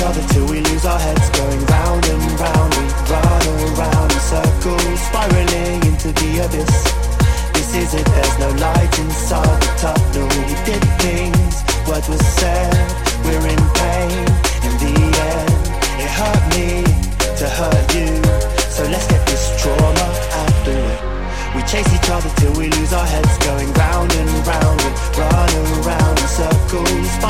TOP >Vinyl >Drum & Bass / Jungle
TOP > Vocal Track
TOP > Deep / Liquid